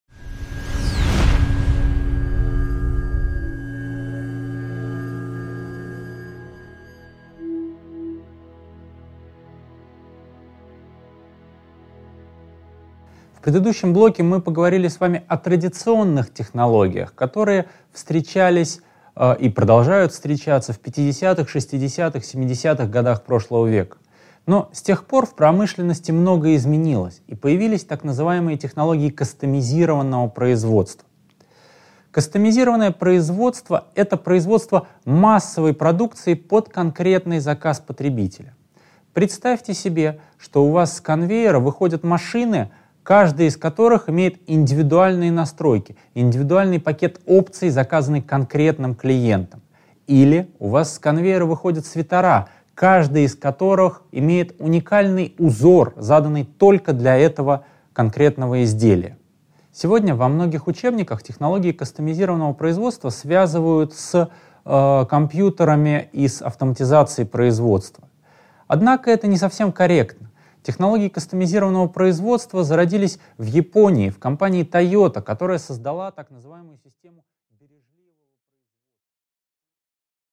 Аудиокнига 5.2. Технология: кастомизированное производство | Библиотека аудиокниг